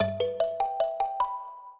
mbira
minuet9-6.wav